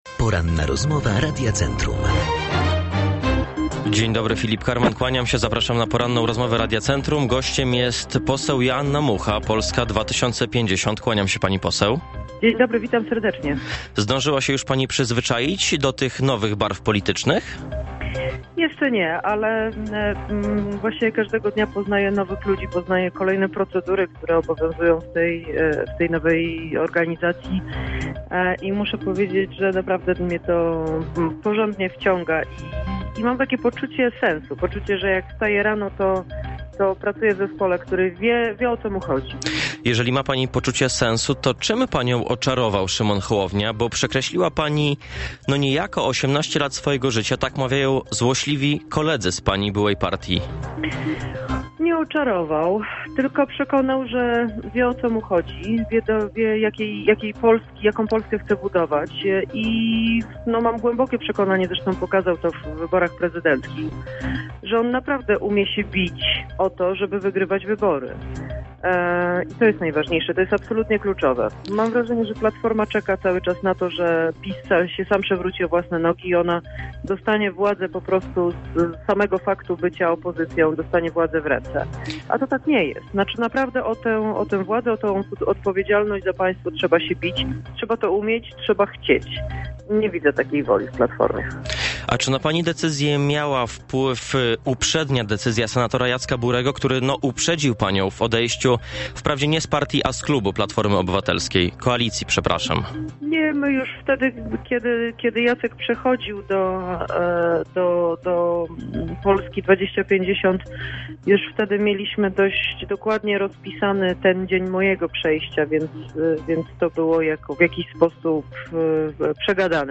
Parlamentarzystka była gościem Porannej Rozmowy radia Centrum.
Ze względu na problemy techniczne nie została nagrana w całości: